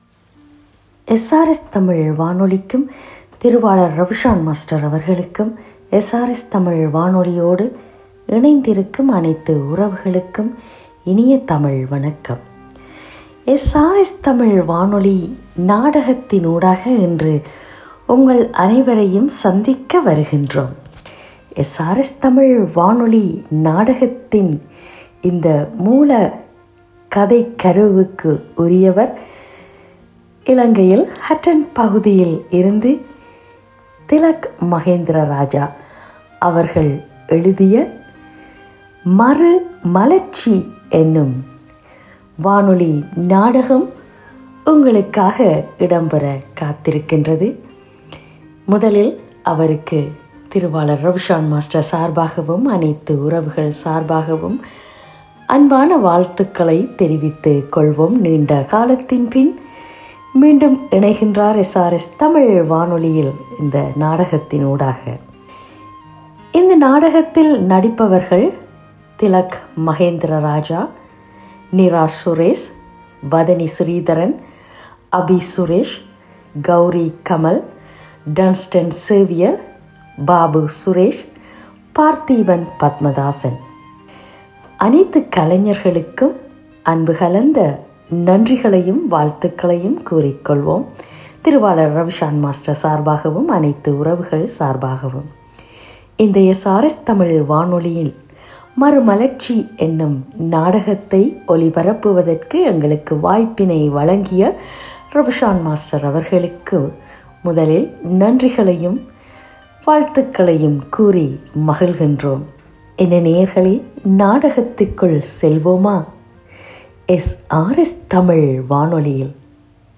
SRS தமிழ் வானொலி நாடகம் மறுமலர்ச்சி 20.04.25 - SRS Tamil Radio